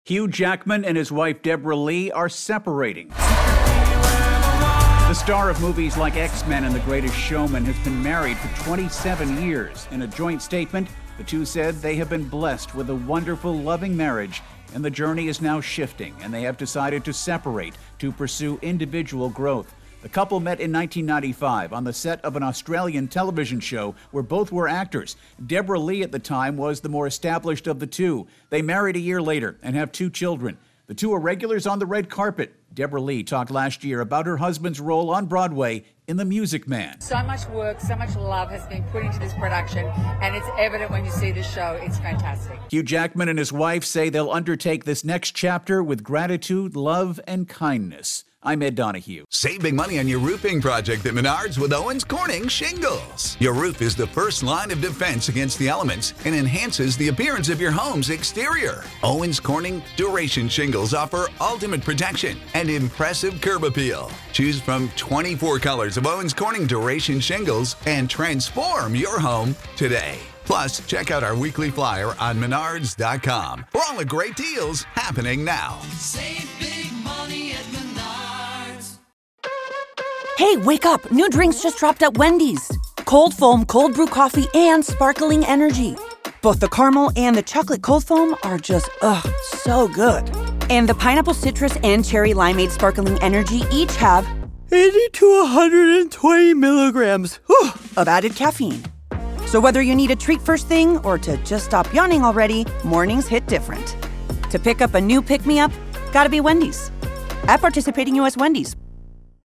((opens with music))